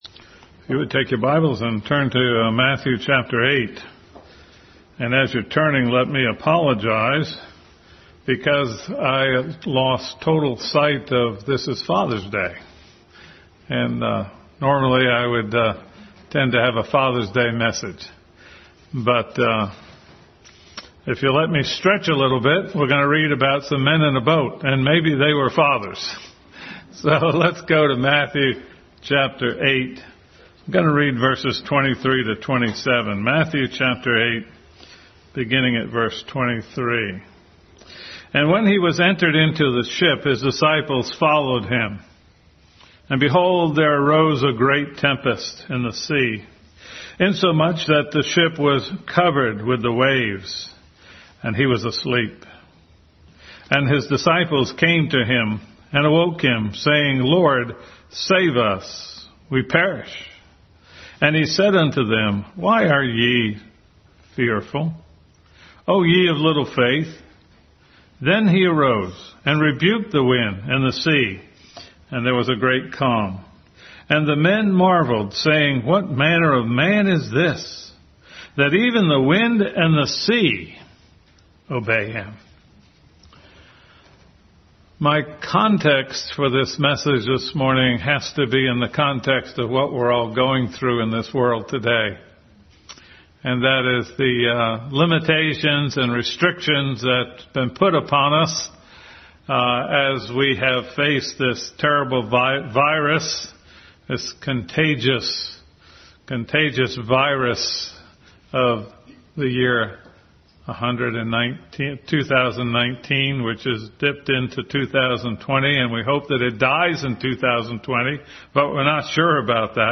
Bible Text: Matthew 8:23-27, Psalm 139:1-6, Mark 4:35-41 | Family Bible Hour Message.